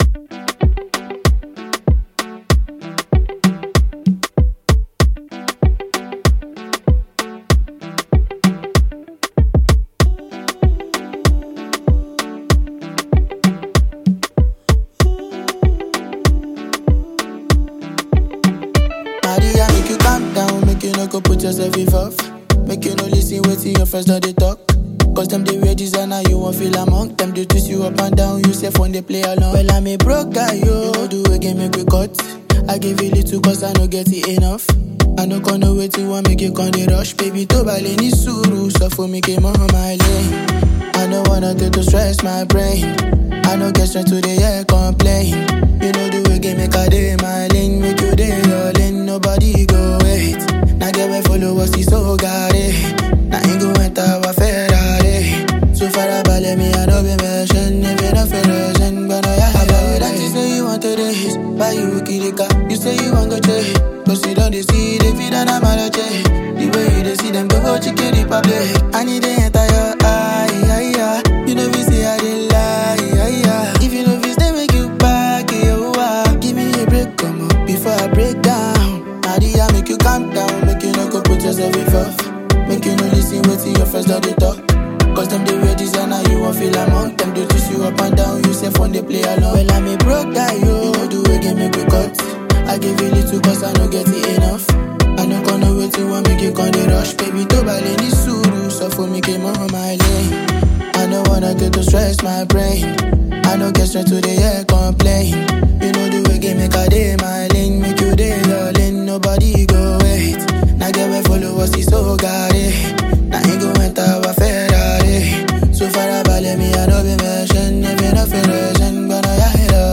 melodious song